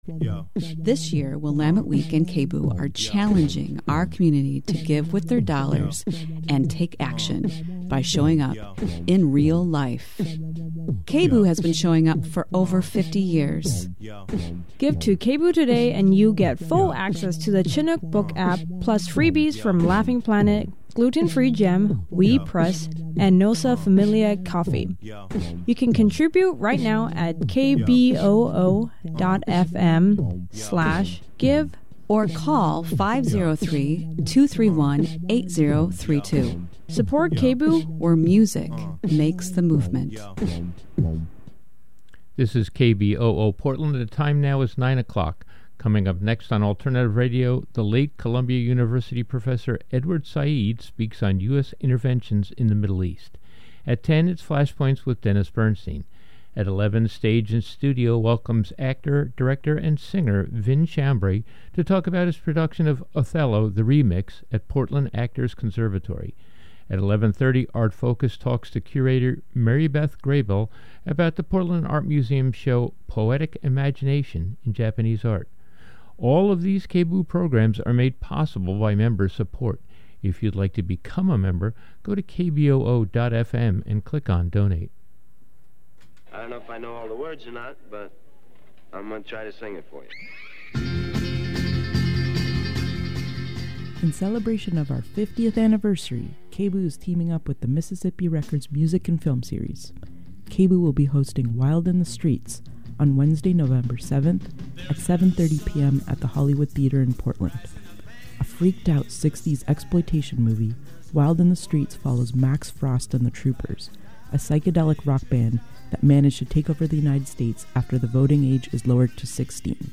This never before broadcast archival program marks the 15th anniversary of Said’s death.